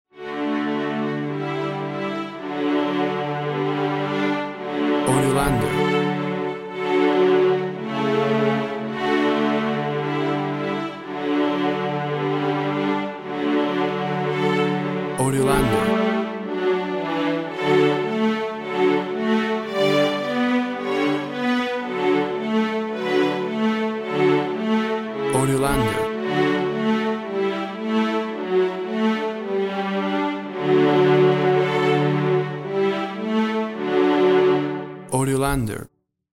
Tempo (BPM) 110